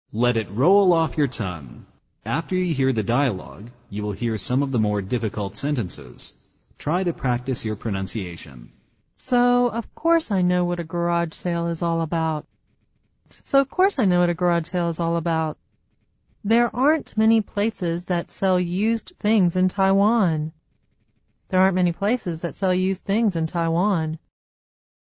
《发音练习》